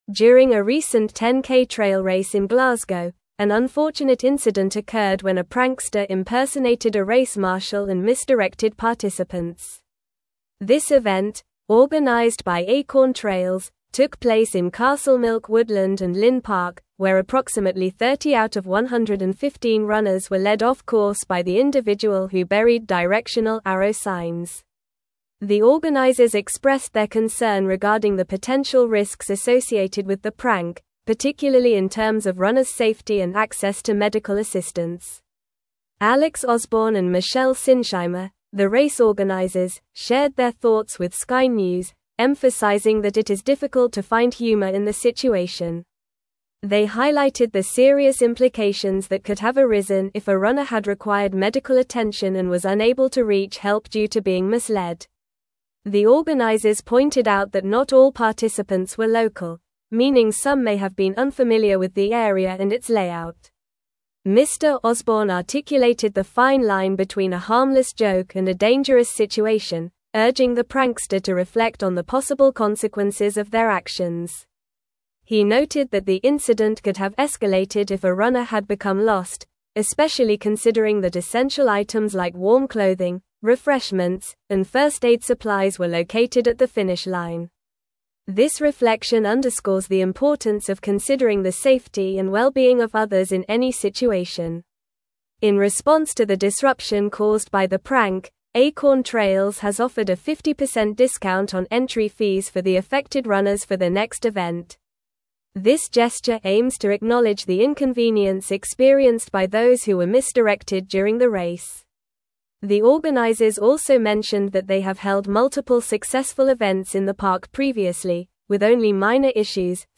Normal
English-Newsroom-Advanced-NORMAL-Reading-Prankster-Disrupts-Glasgow-10k-Trail-Race-Direction.mp3